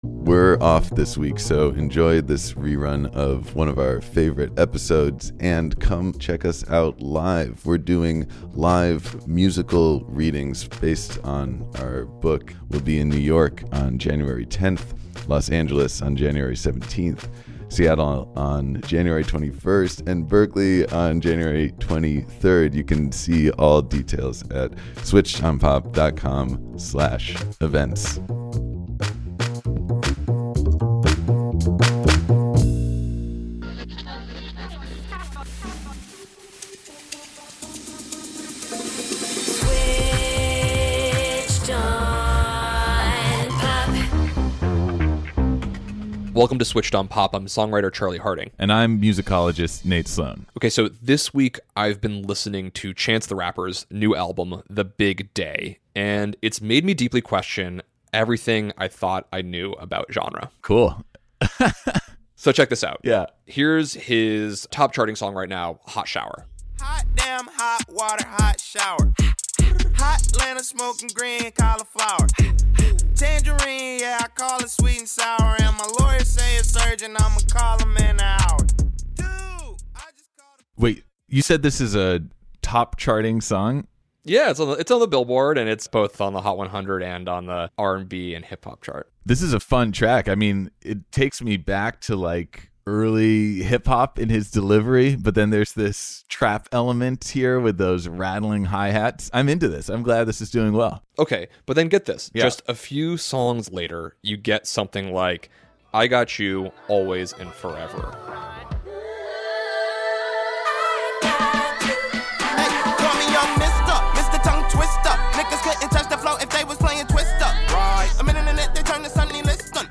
Then we speak with R&B super-producer Oak Felder to understand how R&B is progressing and what it might become.